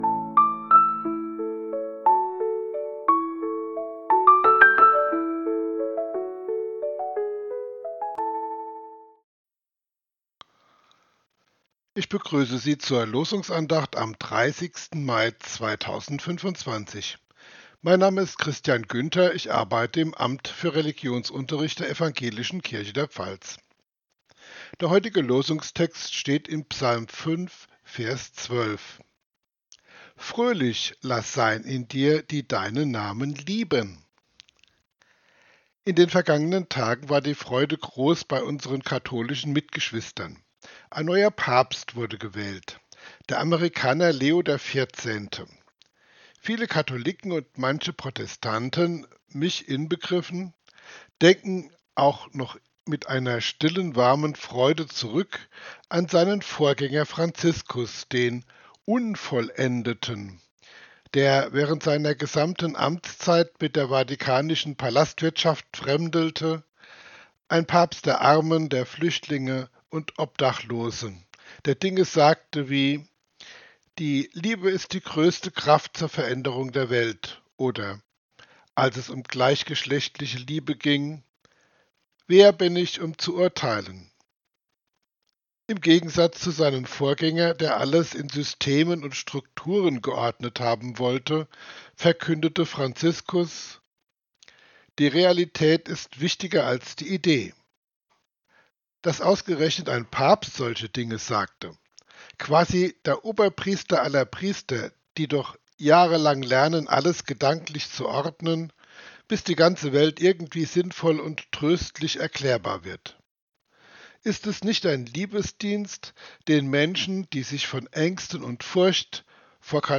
Losungsandacht für Freitag, 30.05.2025